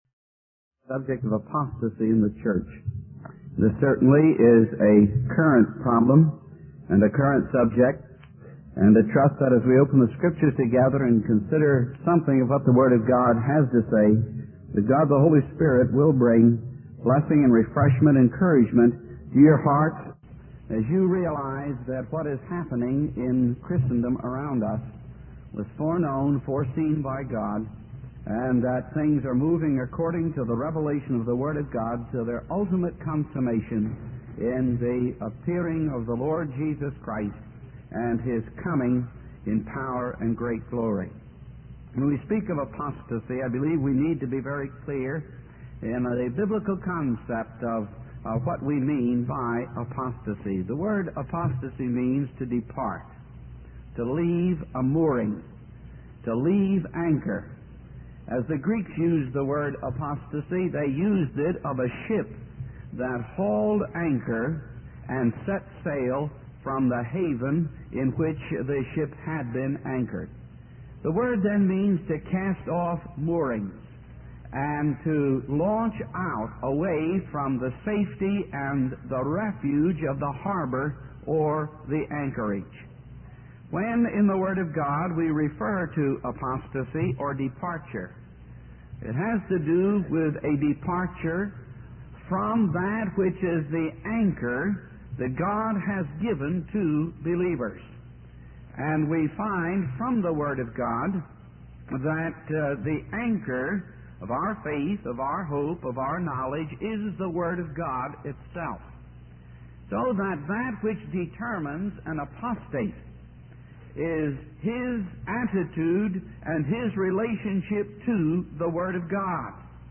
In this sermon, the speaker discusses the concept of apostasy and its origin. He emphasizes that faith cannot be blind and that it is based on knowledge and understanding of the word of God.